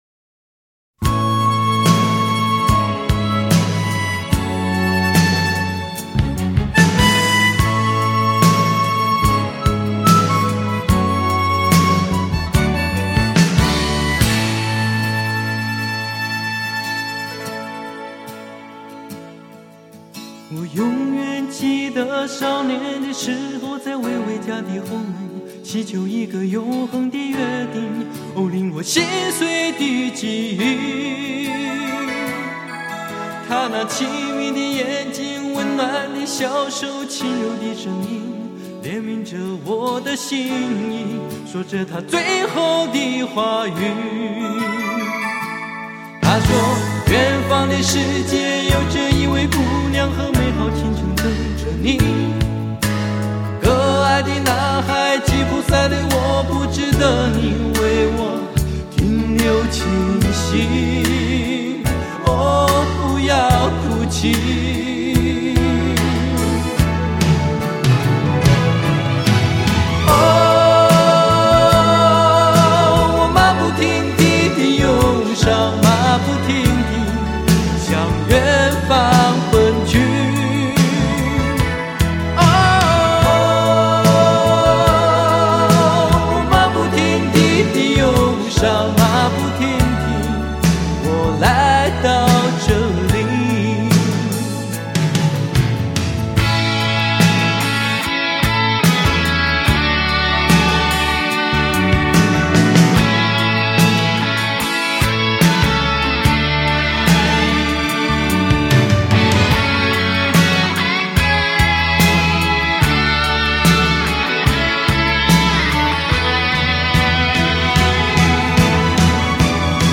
原装母带采用JVC超解析技术处理 日本制造